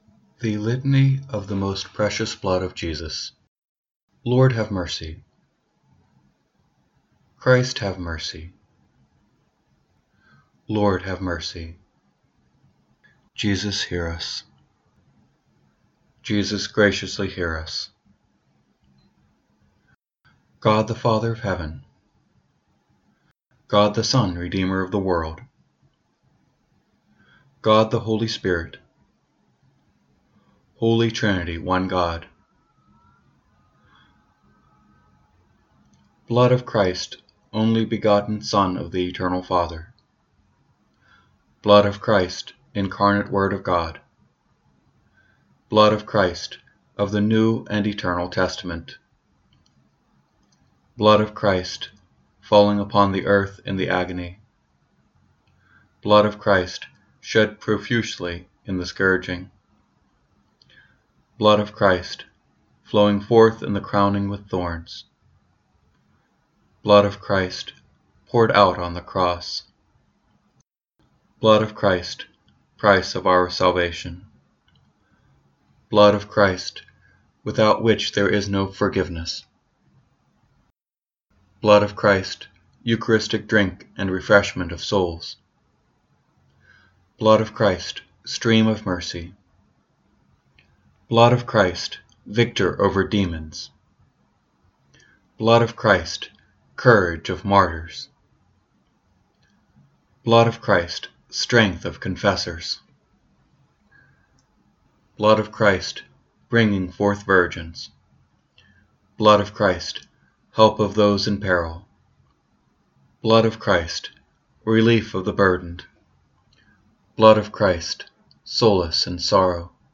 The invocations of the litanies are recorded and the response is left open for the listener to participate.
litany-of-the-most-precious-blood001.mp3